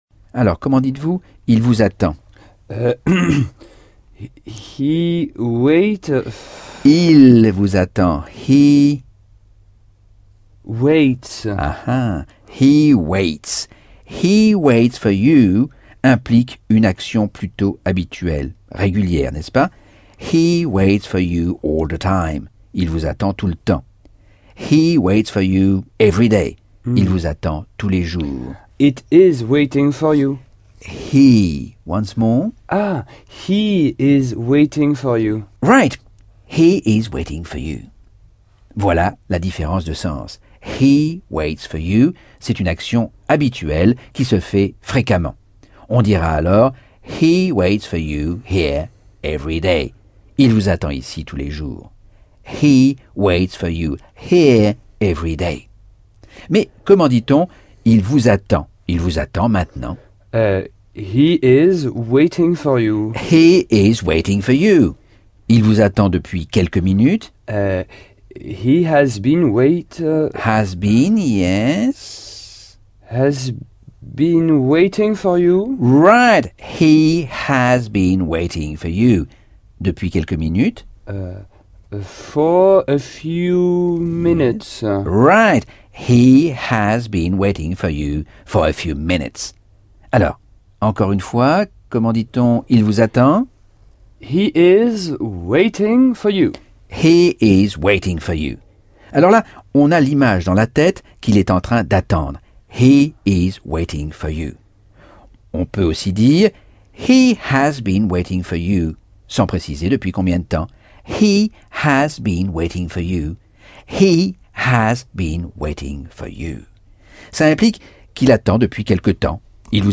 Leçon 11 - Cours audio Anglais par Michel Thomas - Chapitre 10